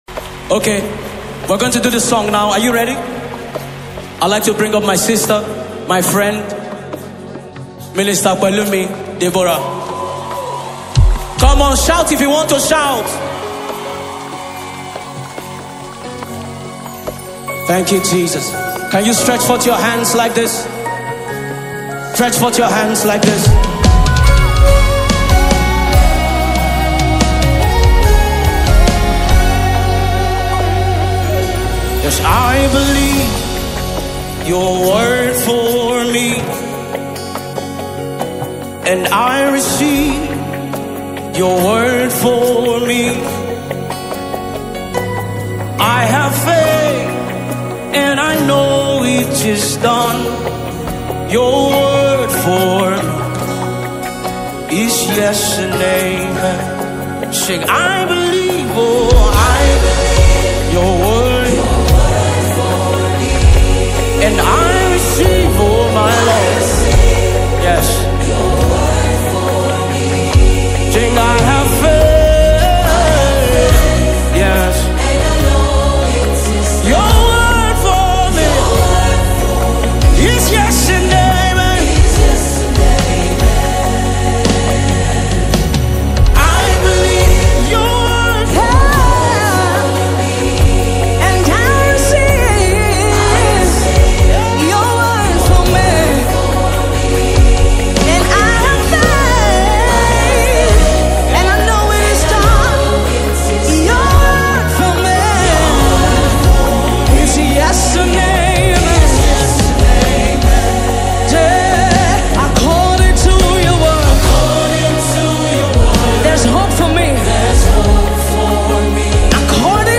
live recording concert